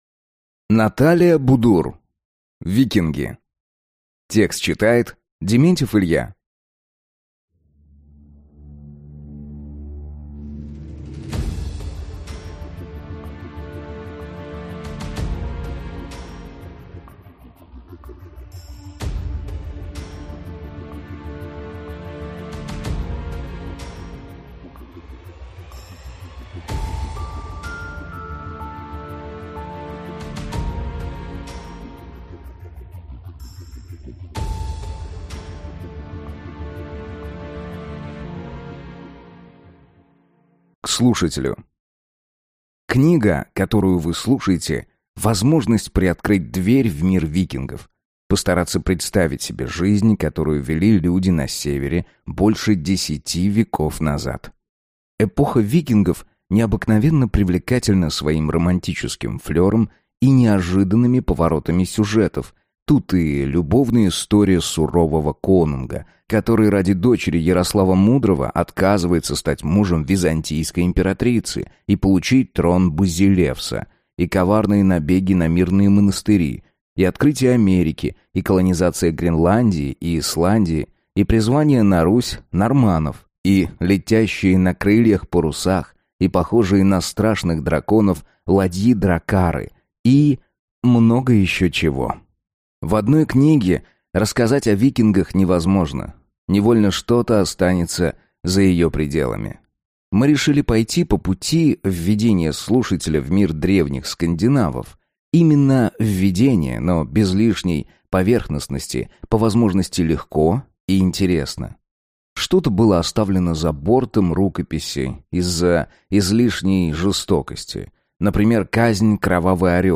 Аудиокнига Викинги | Библиотека аудиокниг